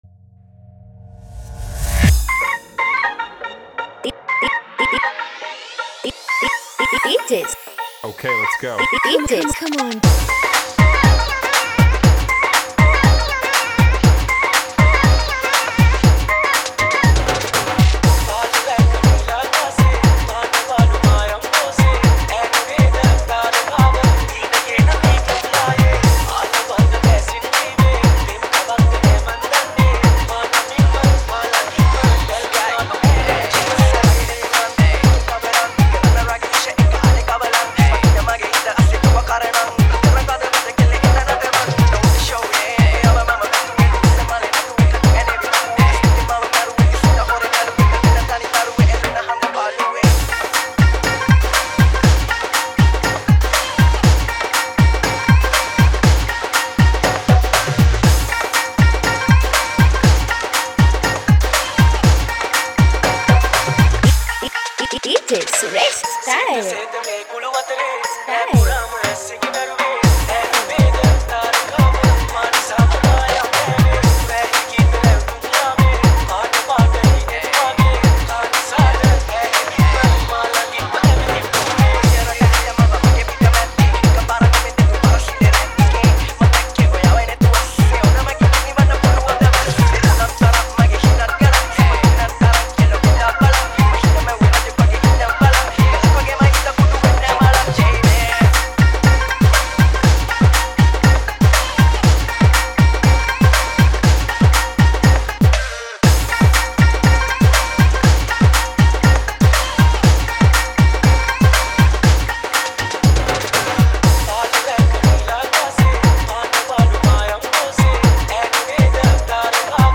Punjab Dance Remix